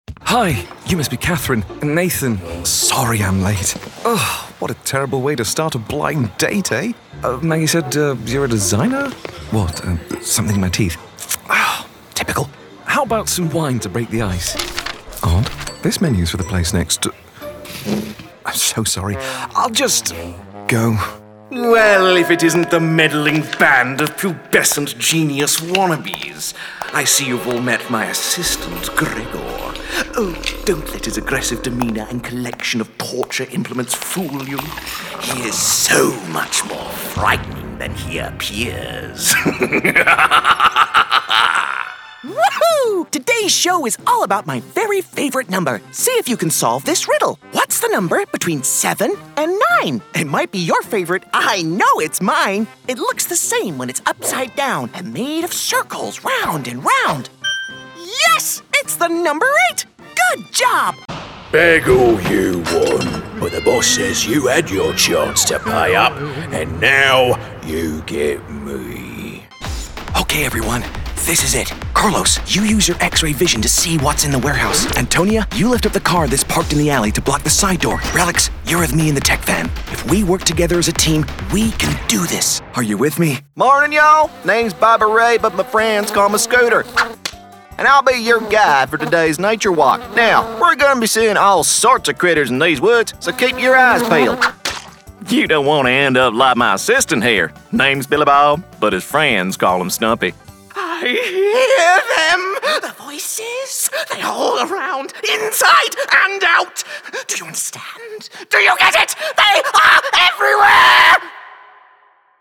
Animation Showreel
Male
Welsh
Bright